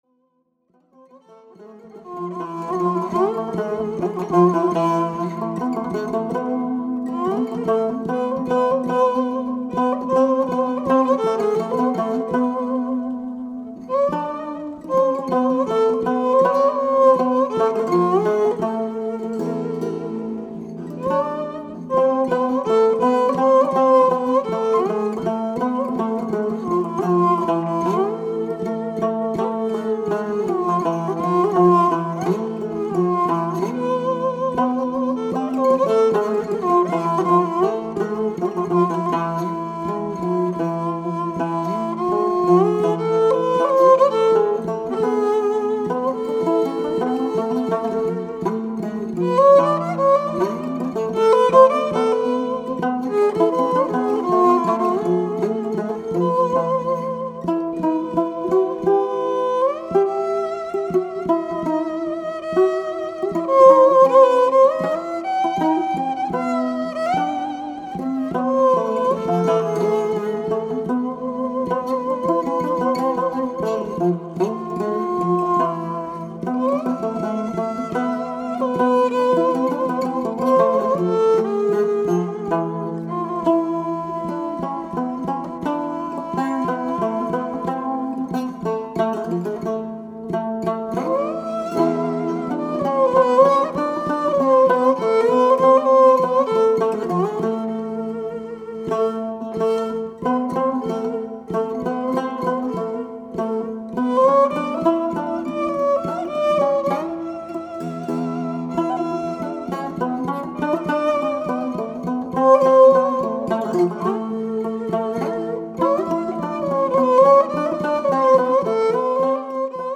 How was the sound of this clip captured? Recorded on October 5 & 6,1999 in Walnut Creek, California